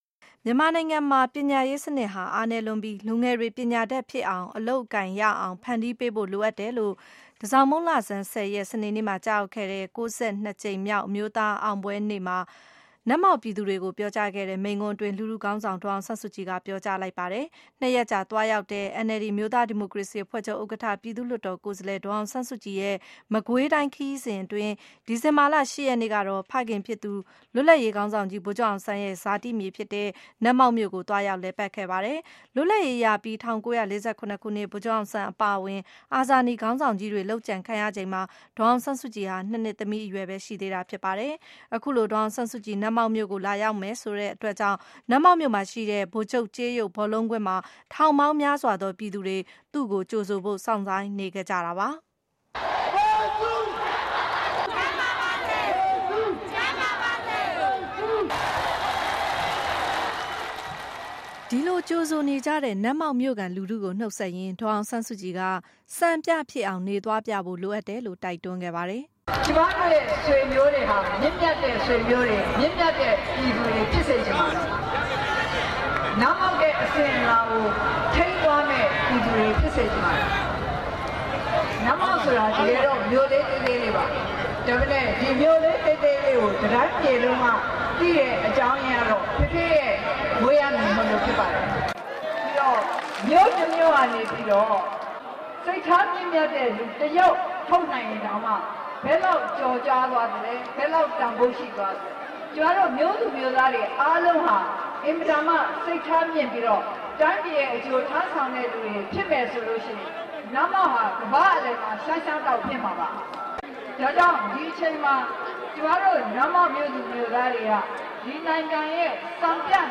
DASSK speech